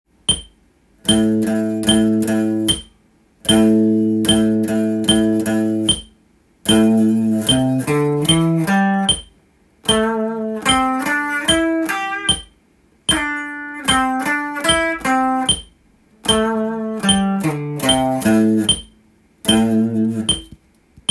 スケールでリズム練習tab
④のリズムは３拍目に休符が来るので